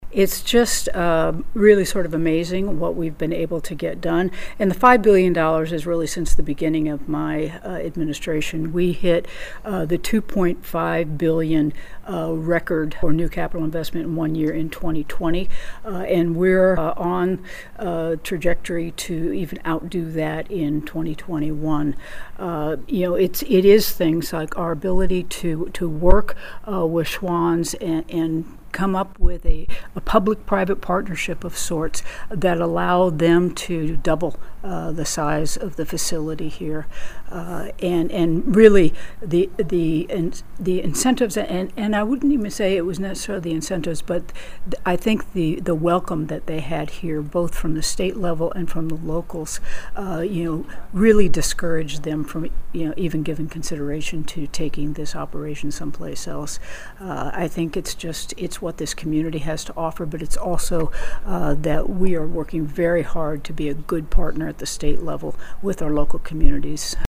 Kansas Governor Laura Kelly made a stop in Salina Monday, speaking at a Rotary Club luncheon and then with the media afterwards.